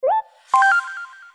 sms4.wav